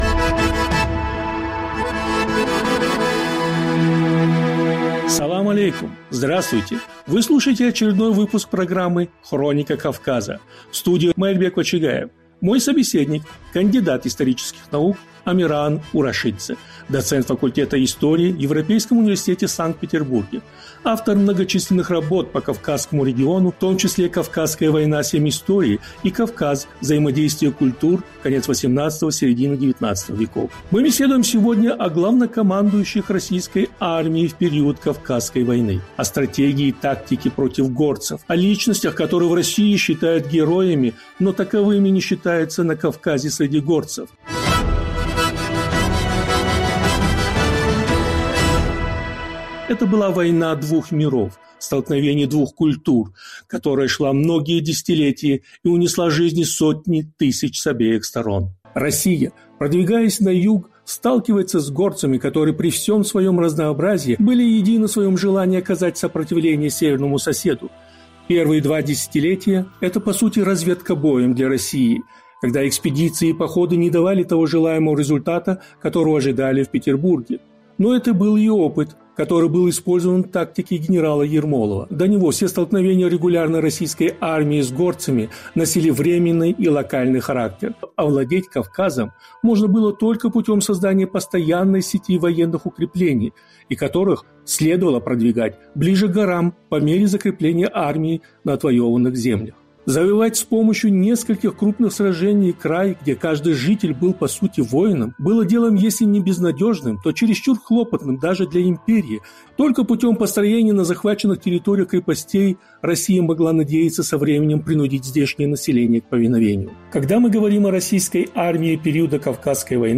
Кто управлял российской армией в Кавказской войне? Почему на слуху только фамилия Ермолова? Повтор эфира от 09 мая 2021 года.